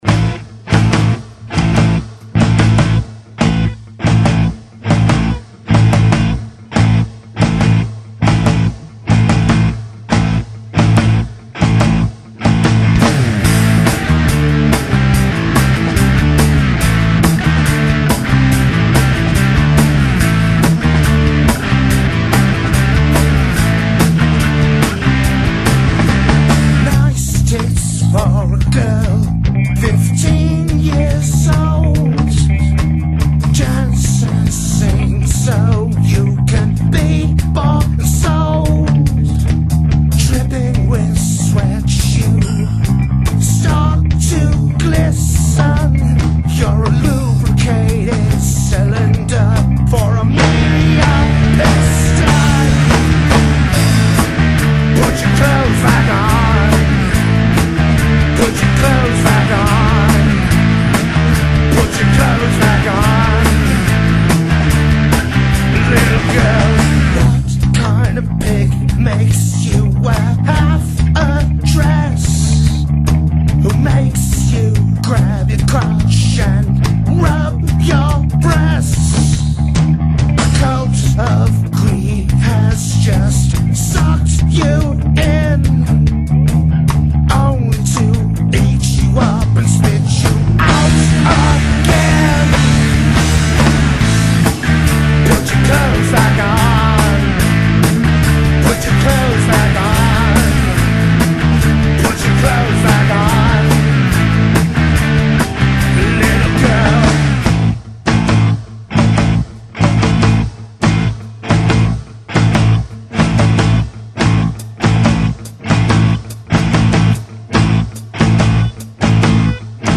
Rock & Roll
Indy
Glam trash